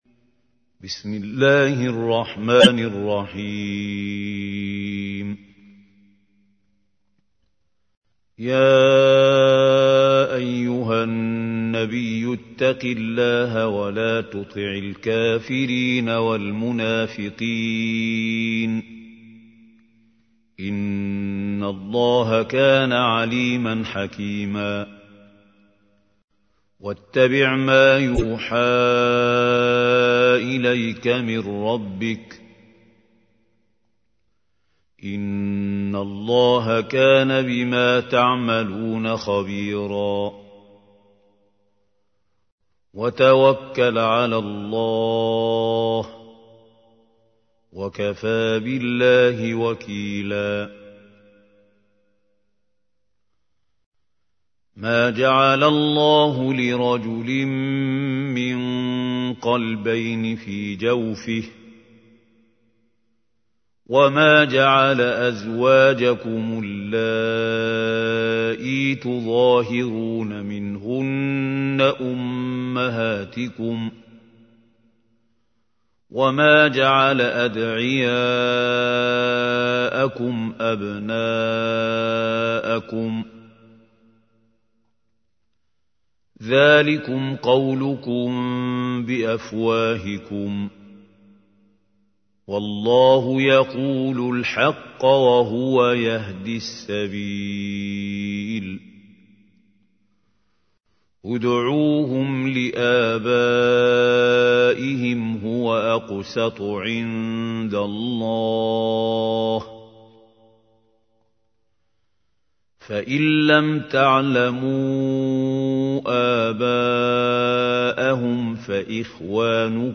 تحميل : 33. سورة الأحزاب / القارئ محمود خليل الحصري / القرآن الكريم / موقع يا حسين